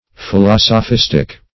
Meaning of philosophistic. philosophistic synonyms, pronunciation, spelling and more from Free Dictionary.
Philosophistic \Phi*los`o*phis"tic\
philosophistic.mp3